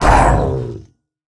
sounds / mob / ravager / hurt1.ogg
hurt1.ogg